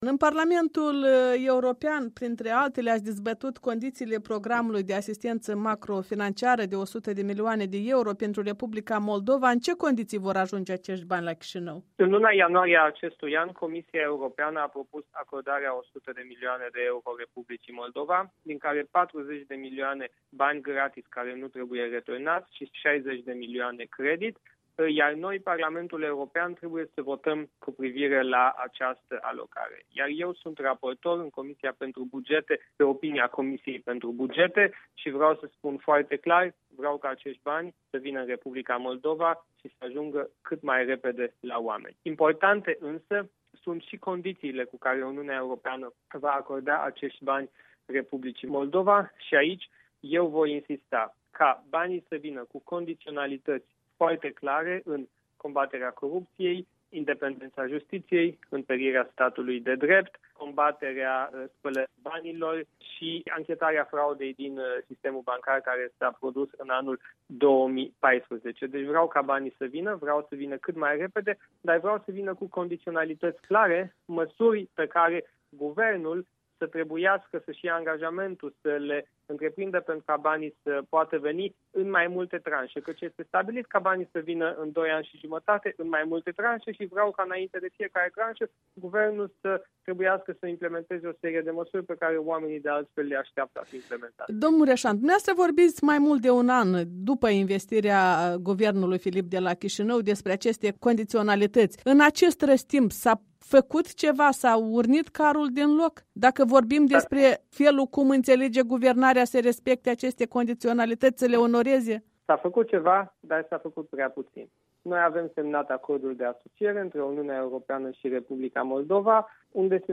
Interviu cu Siegfried Mureșan